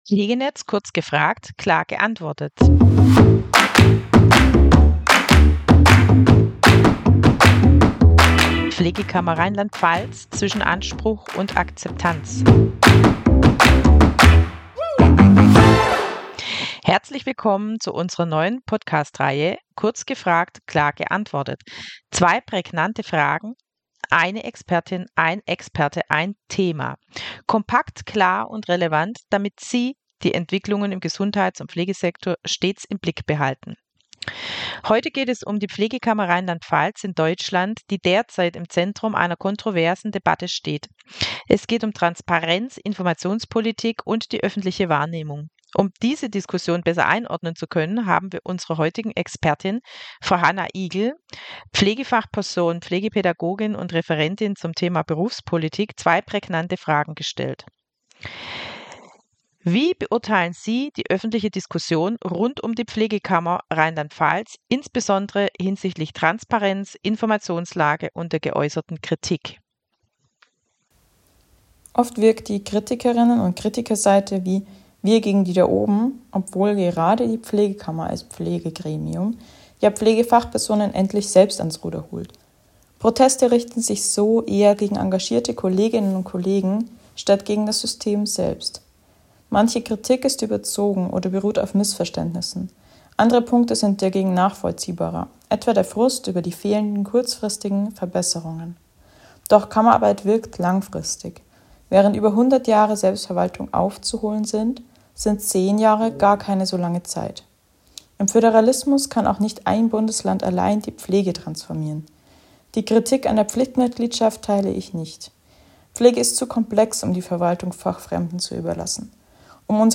Zwei pointierte Fragen, eine Expertin oder ein Experte, ein Thema – kompakt, klar und relevant.